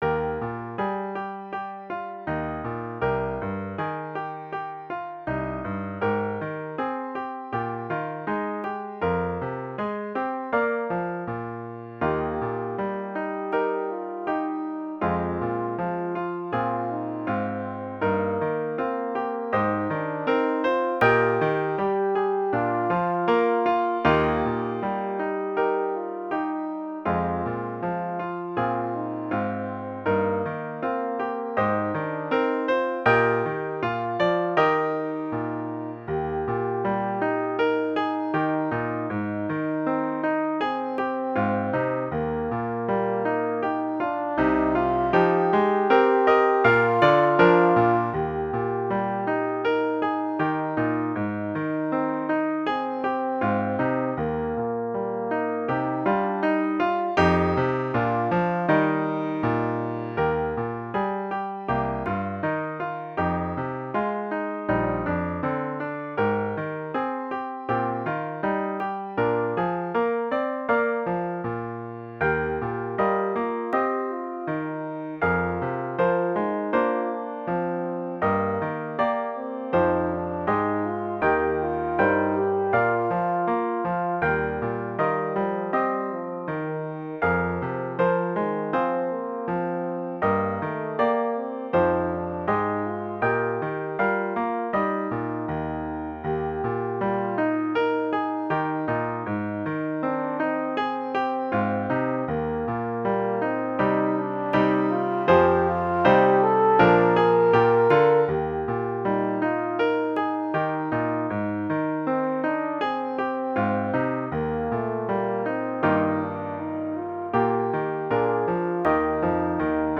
Intermediate Instrumental Solo with Piano Accompaniment.
Christian, Gospel, Sacred.
gentle, meditative mood.